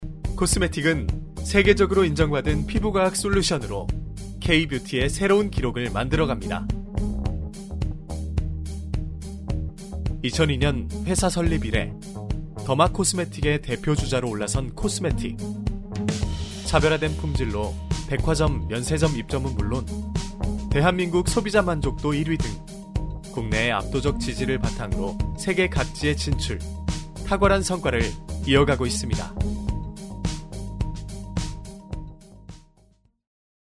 성우샘플
한국어성우
스마트한